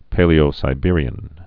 (pālē-ō-sī-bîrē-ən)